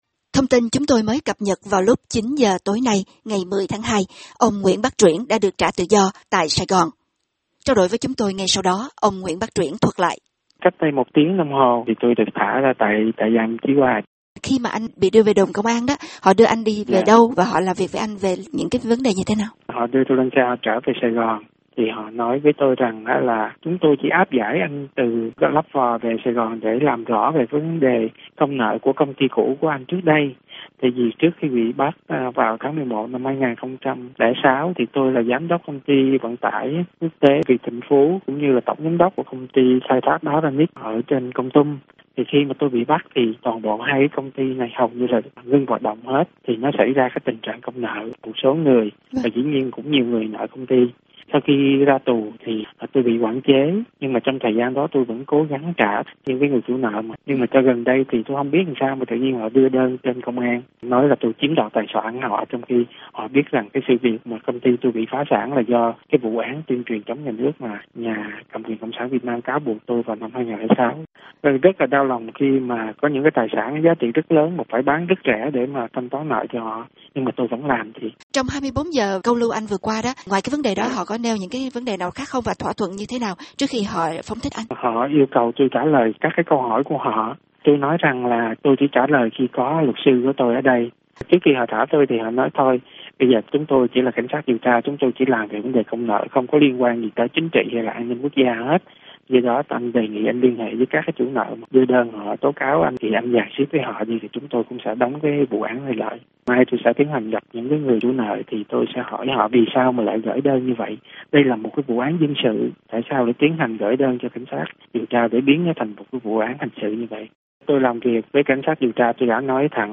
Toàn bộ nội dung cuộc phỏng vấn sẽ được cập nhật trong giây lát.